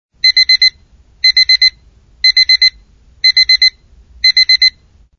TraditionalAlarm.mp3